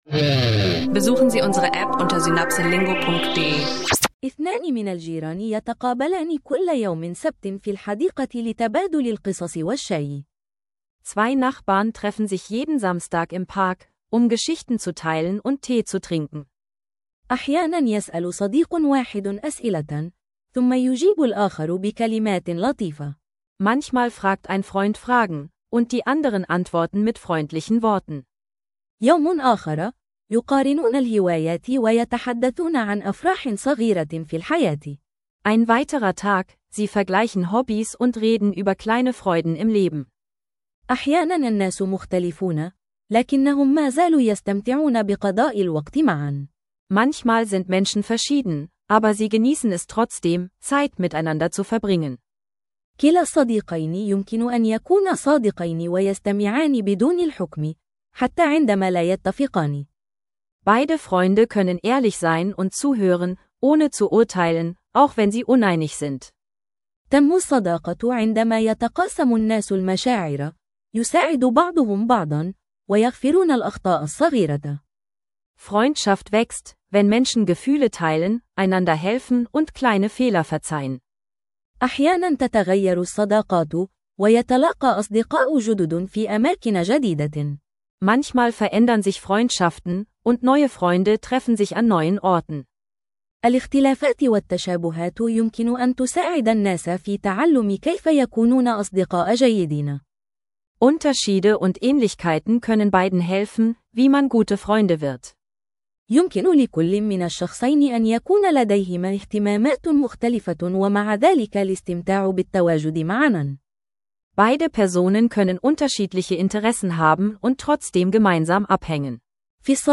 und Phrasen, höre authentische Dialoge und verbessere dein Spoken